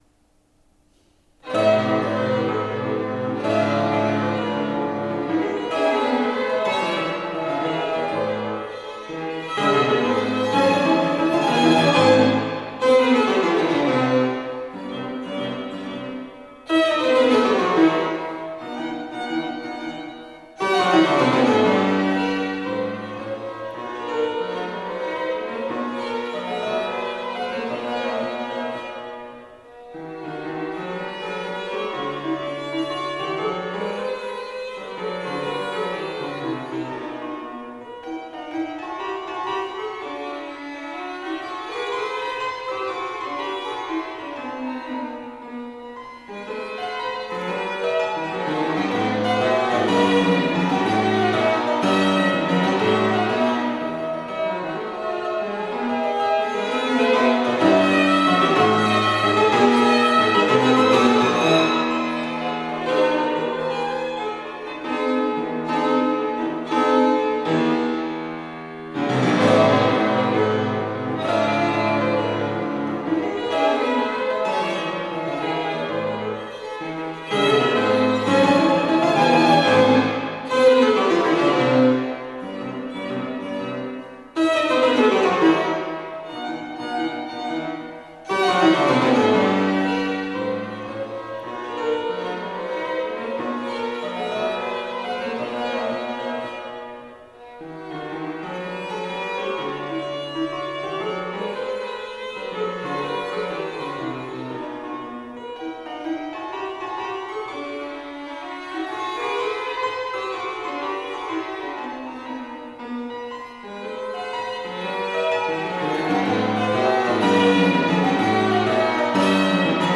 violin
fortepiano
Sonatas for violin and fortepiano by Beethoven, Hummel and Ries
– Presto